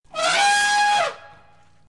Elephant Trumpets Growls 6047 (audio/mpeg)